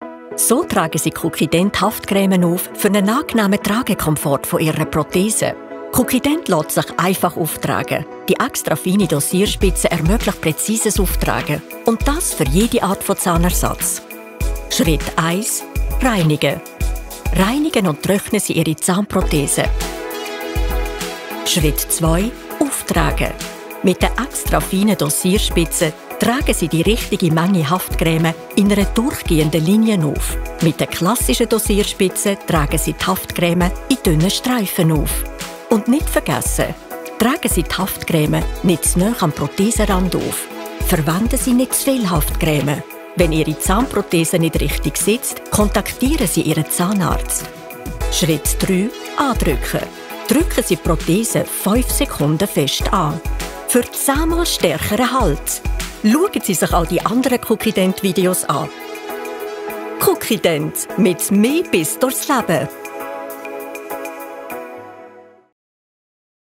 OFF-Kommentar Schweizerdeutsch (AG)